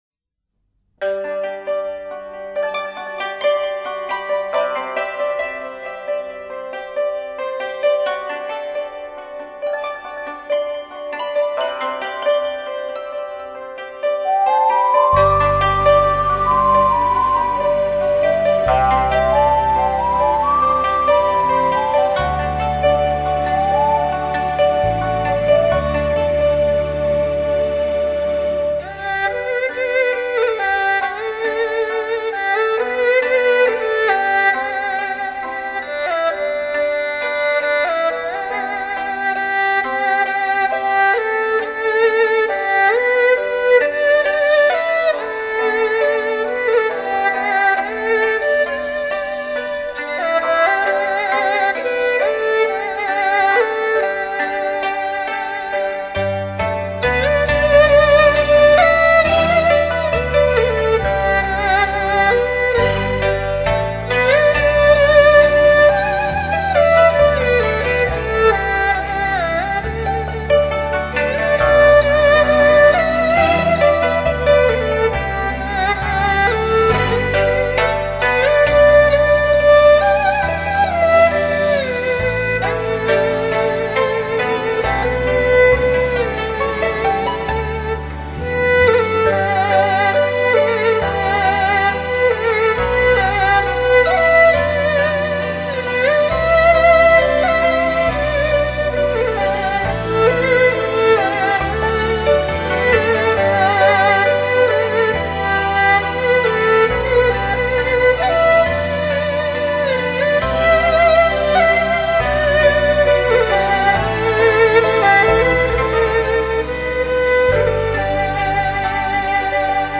版    本:二胡演奏流行歌曲